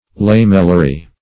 Search Result for " lamellary" : The Collaborative International Dictionary of English v.0.48: Lamellary \Lam"el*la*ry\, a. Of or pertaining to lamella or to lamellae; lamellar.
lamellary.mp3